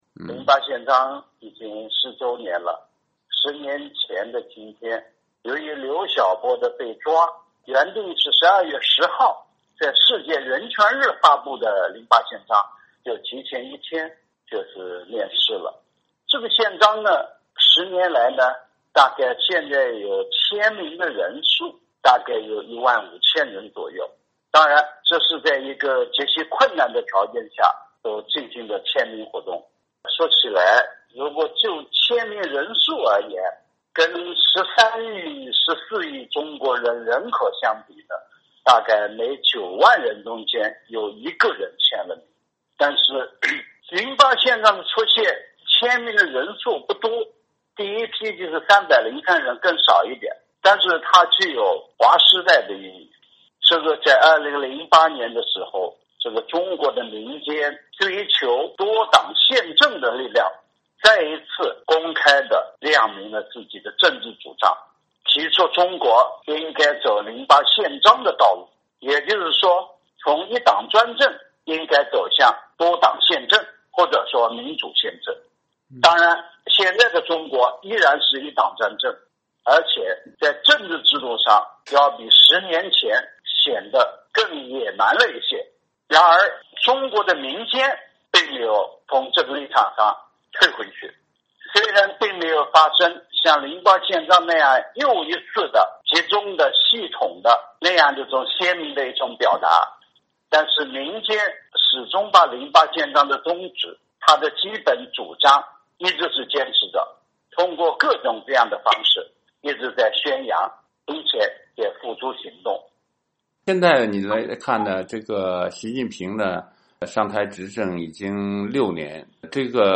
（据电话录音整理，受访者观点不代表美国之音）